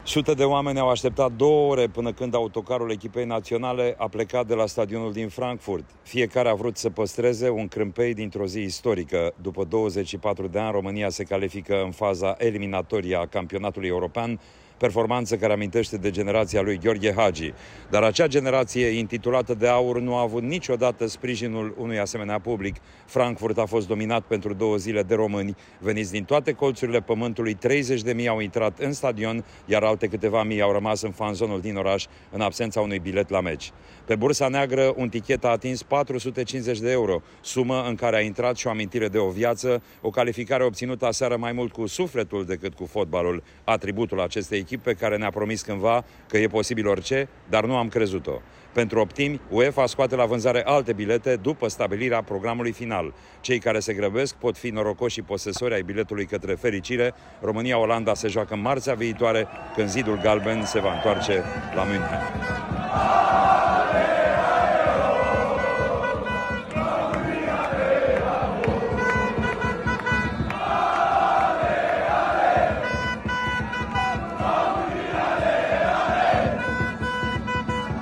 România a terminat pe primul loc în grupa E şi va juca, marţi, în optimile de finală, cu selecţionata Olandei. O corespondenţă de la trimisul RRA